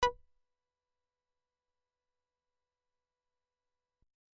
Akai AX80 Sync Bass " Akai AX80 Sync Bass B6 (95LGGE)
标签： MIDI网速度80 B6 MIDI - 注意-95 赤-AX80 合成器 单票据 多重采样
声道立体声